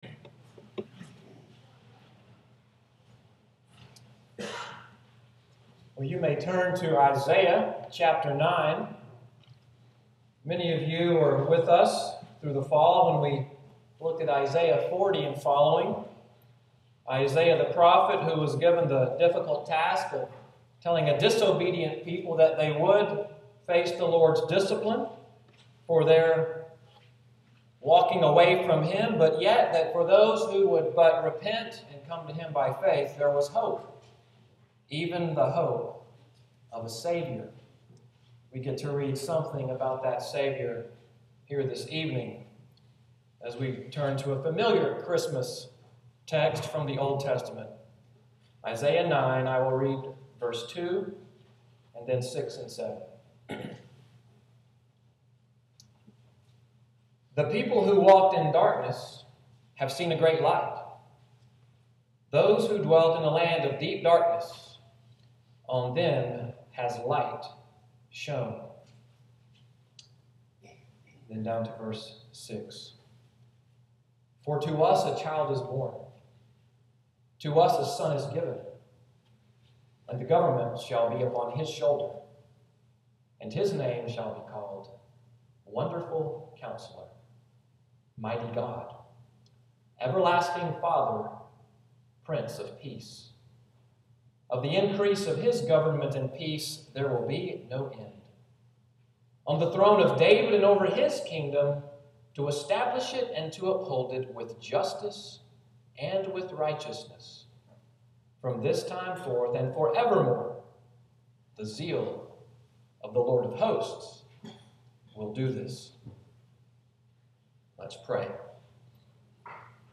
Christmas Eve sermon, “What A Name!” December 24, 2014.